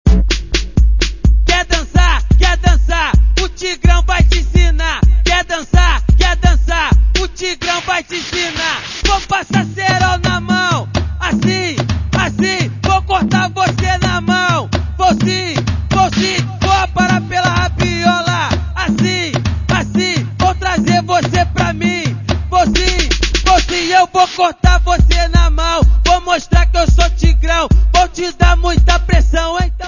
La répèt' finit en bal avec DJ. On arrive vite au funk mais attention, du funk brésilien : des paroles et chorégraphies plutôt explicites, voir salaces.